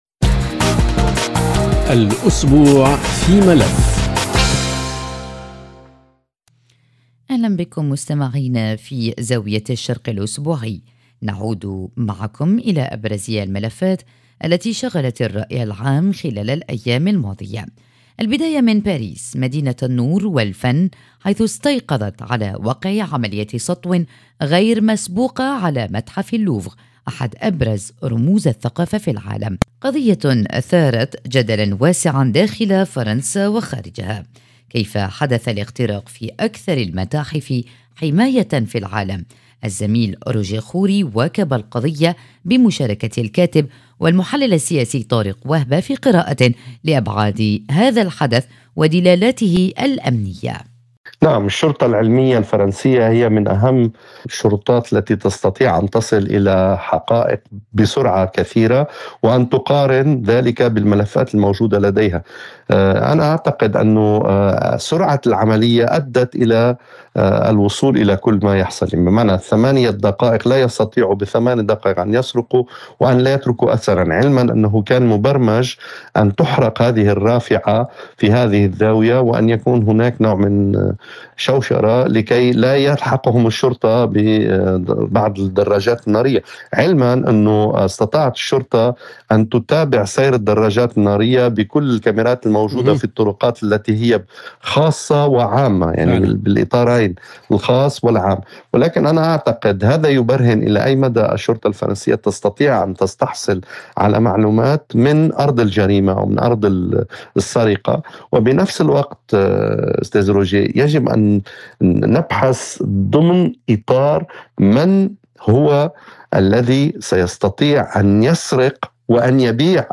في هذه الحلقة ، نتابع التحليلات والقراءات العميقة لهذه الملفات مع نخبةٍ من الخبراء والمحلّلين عبر عبر راديو أوريات-إذاعة الشرق.